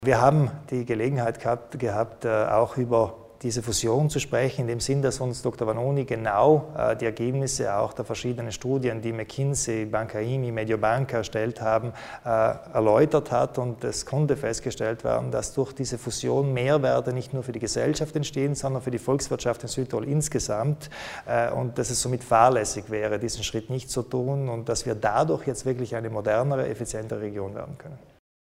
Landeshauptmann Kompatscher über die Zukunft des Energiesektors